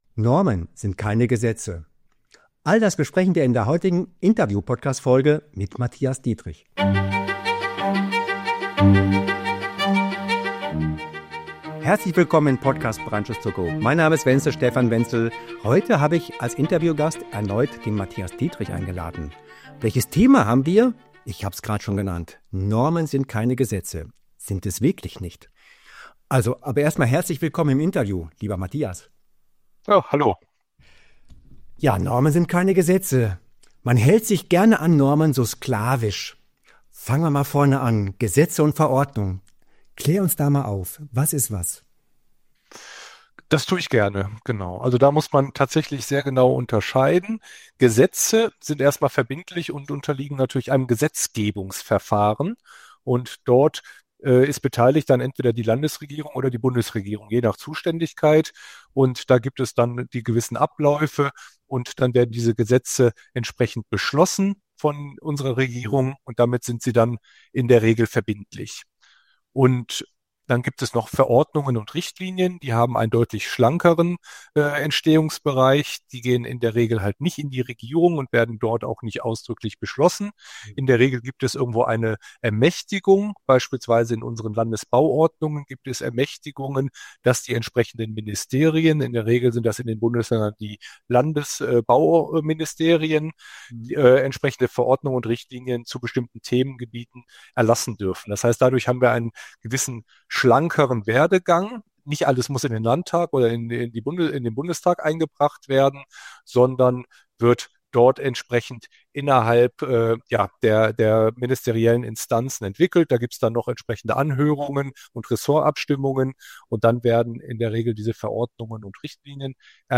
Ein fachlich starkes Gespräch über Verantwortung, Kompetenz und pragmatische Lösungen im Brandschutzalltag.